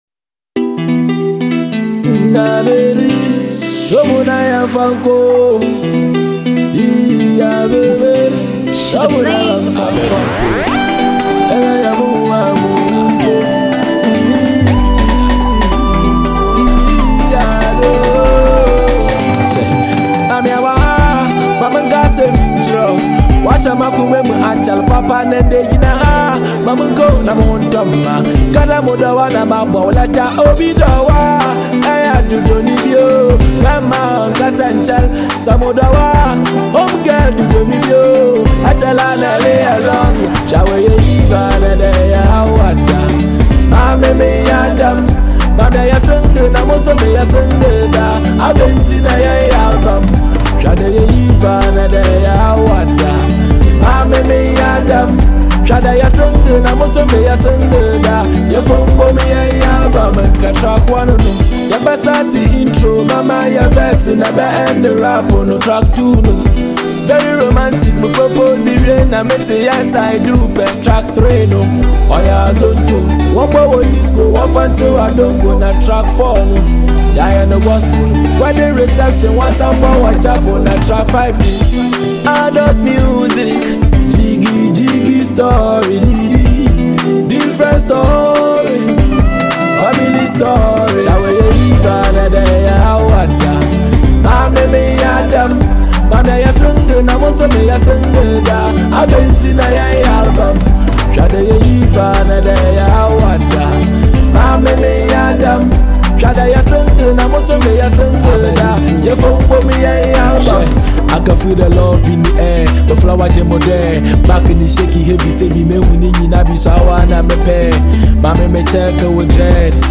love track